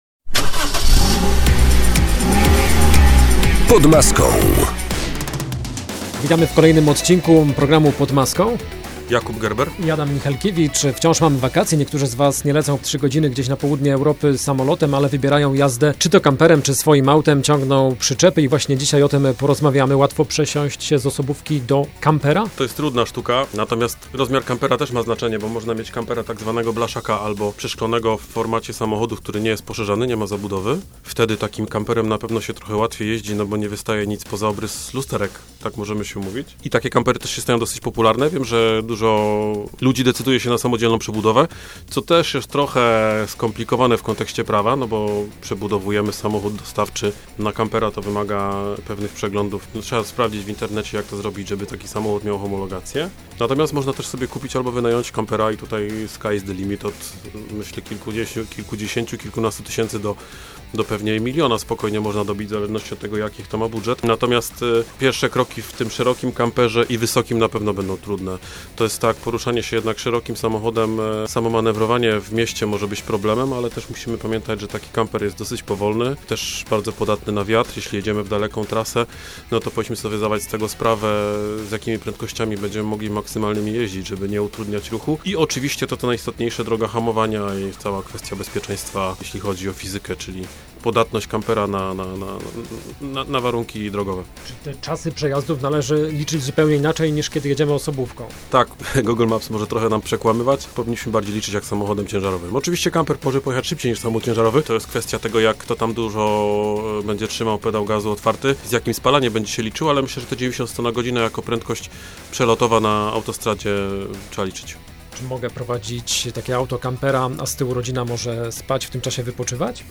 Kamper czy przyczepa campingowa? Co wybrać na wakacyjną podróż? O tym rozmawiamy z naszym ekspertem